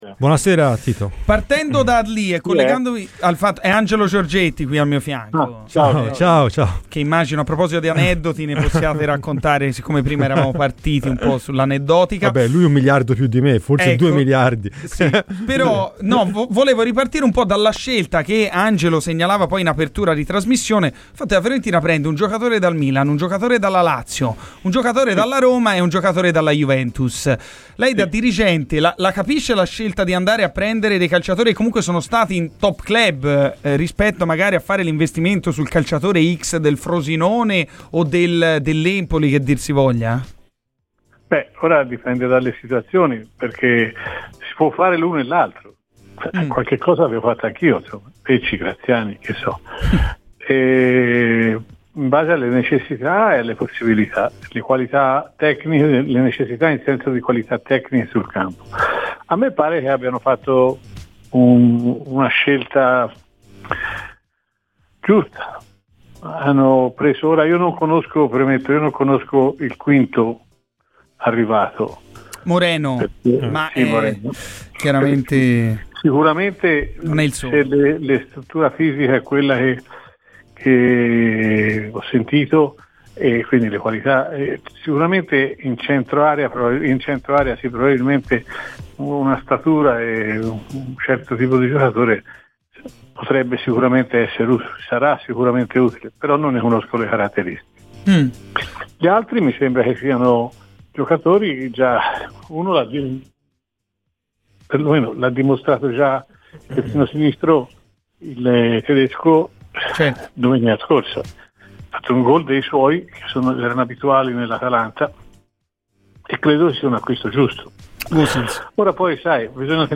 ASCOLTA IL PODCAST PER L'INTERVENTO INTEGRALE